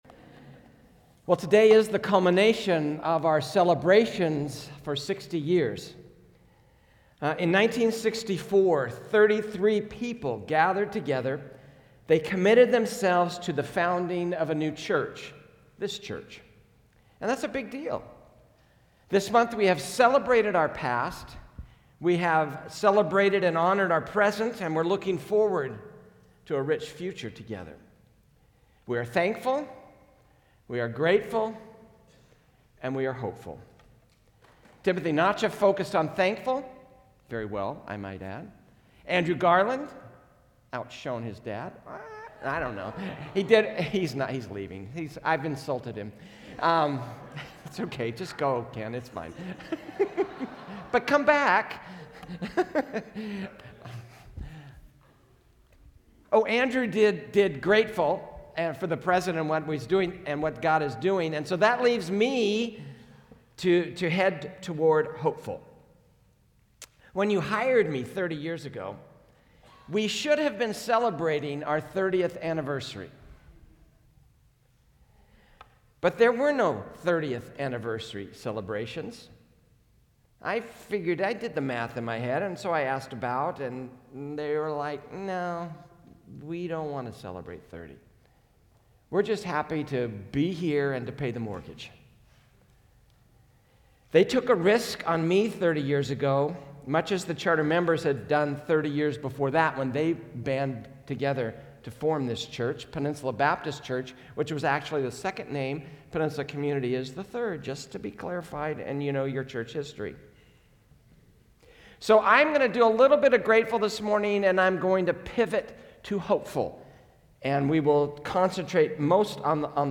A message from the series "Diamond Jubilee." It has been 60 years since a group of believers gathered to give birth our church family.